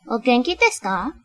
描述：我是美国人，非常不会说日语。这些只是一些基本的短语。对不起任何发音问题。
Tag: 说话 日本 声音 女孩